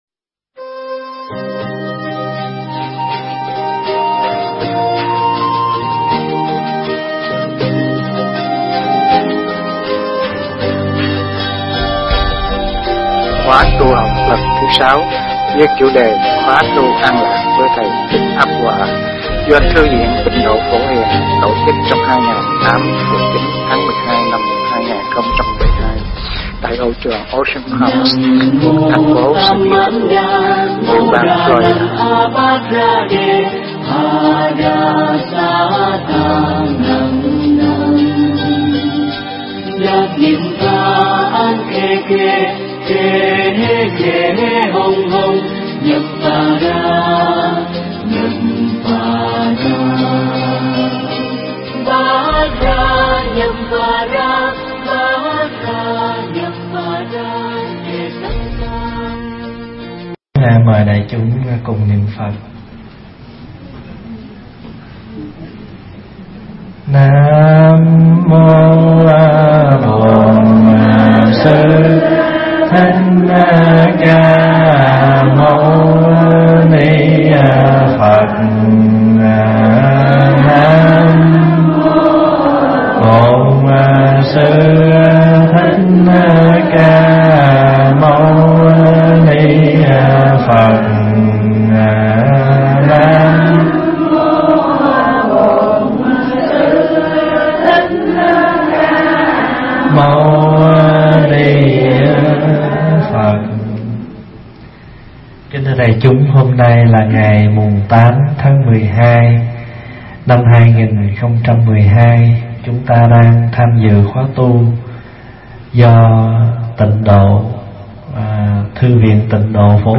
thuyết giảng tại Thư Viện Tịnh Độ Phổ Hiền, ngày 8 tháng 12 năm 2012